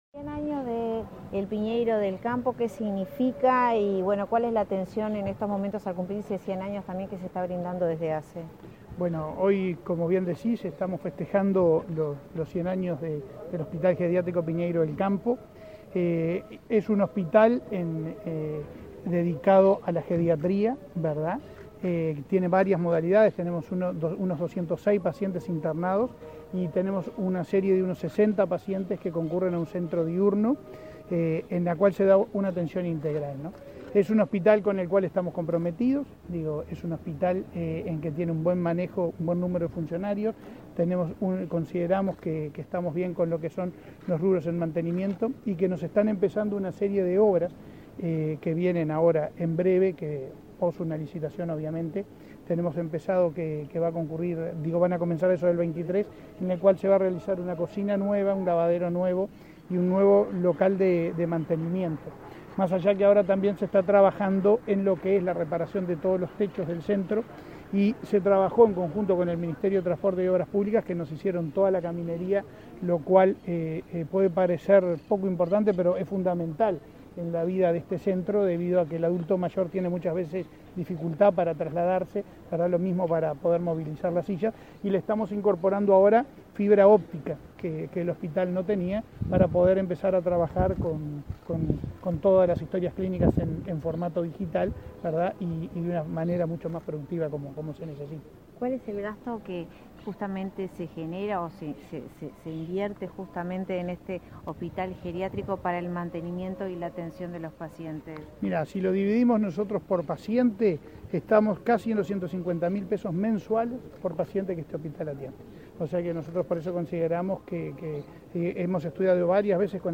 Declaraciones a la prensa del presidente de ASSE, Leonardo Cipriani
Tras participar en el acto de aniversario del Hospital Centro Geriátrico Dr. Luis Piñeyro del Campo, este 14 de noviembre, el presidente de la